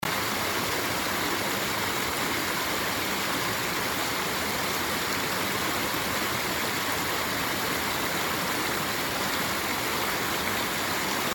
However, this scene is on top of a steep stream bank, just above a noisy cascade. I added the audio of the cascade I recorded at this scene.